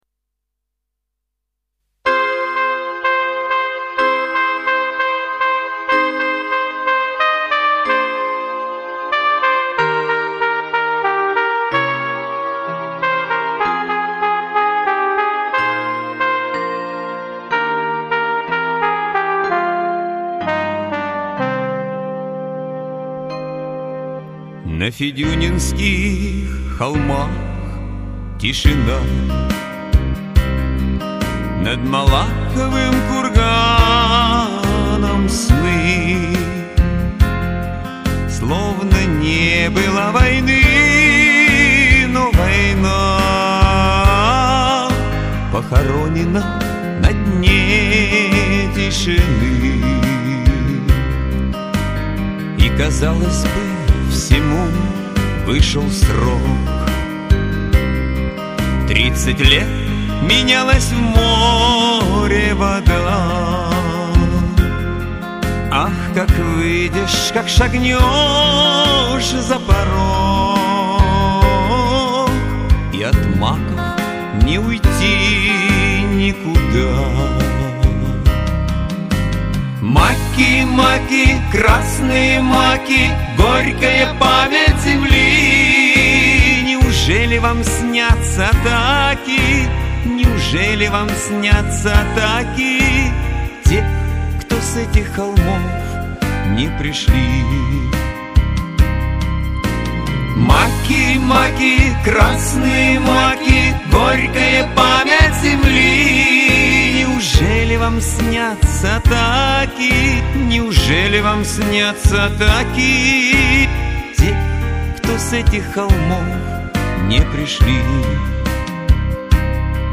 Оба исполнения мне понравились..